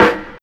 • Nice Trap Acoustic Snare B Key 50.wav
Royality free snare sound tuned to the B note.
nice-trap-acoustic-snare-b-key-50-HR3.wav